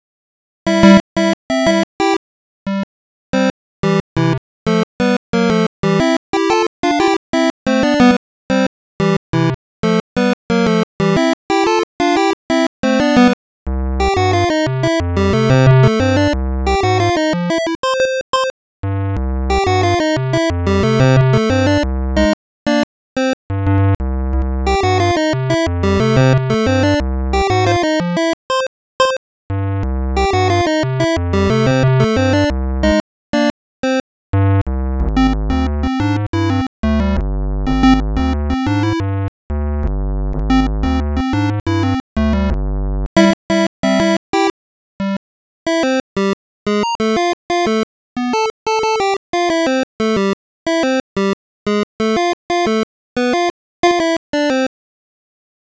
Square Wave